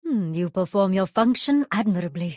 ice-cream-alert.mp3